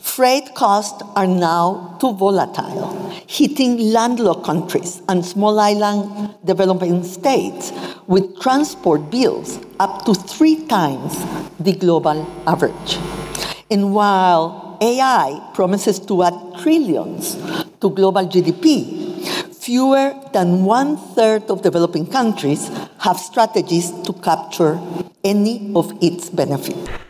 Addressing this year’s UN Trade and Development (UNCTAD) Summit, Secretary-General Rebeca Grynspan warned that developing countries are being held back by a $31 trillion debt burden.